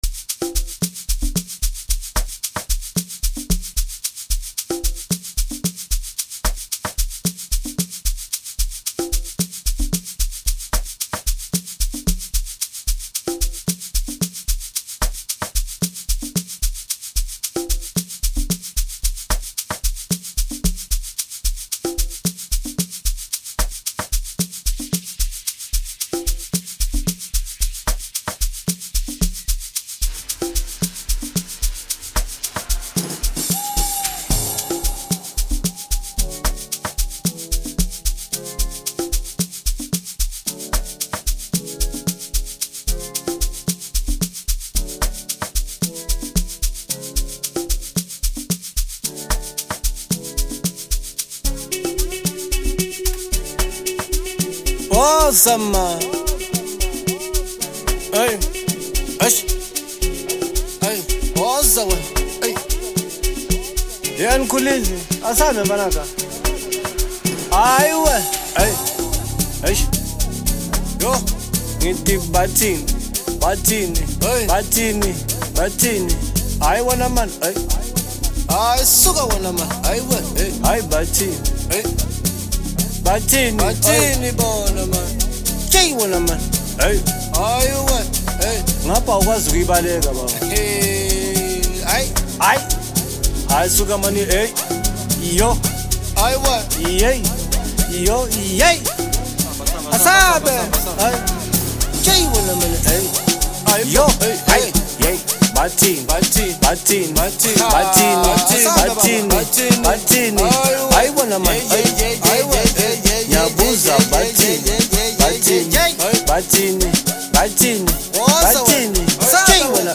Home » Amapiano » Deep House » Hip Hop » Latest Mix